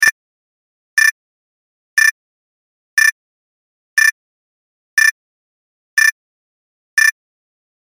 دانلود صدای هشدار 4 از ساعد نیوز با لینک مستقیم و کیفیت بالا
جلوه های صوتی